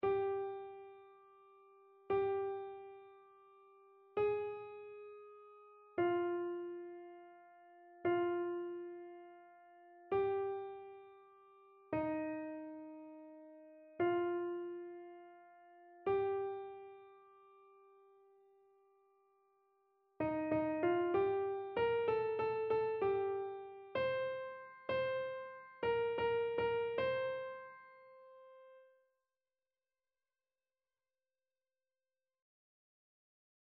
SopranoAlto
annee-abc-fetes-et-solennites-presentation-du-seigneur-psaume-23-soprano.mp3